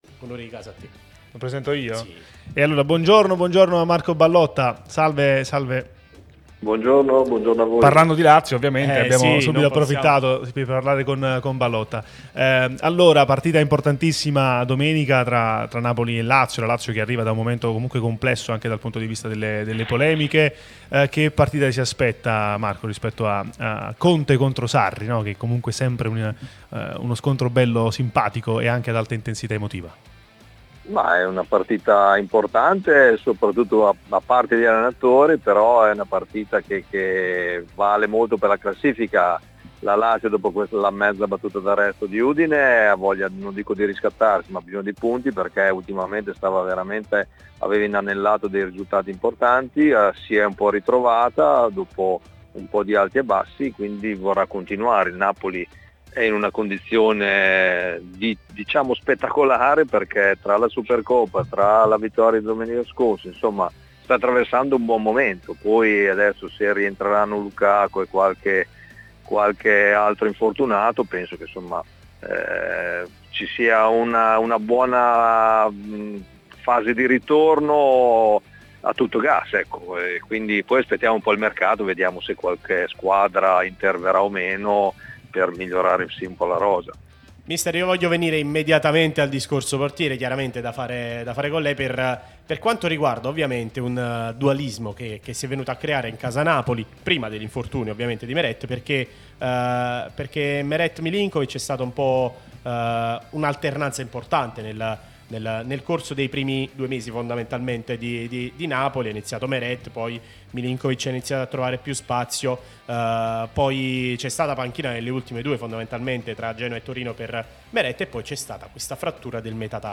Marco Ballotta, ex portiere tra le altre della Lazio, è intervenuto nel corso del Bar di Tuttonapoli sulla nostra Radio Tutto Napoli, prima radio tematica sul Napoli, in onda tutto il giorno, che puoi ascoltare/vedere qui sul sito o sulle app (qui per Iphone/Ipad o qui per Android): "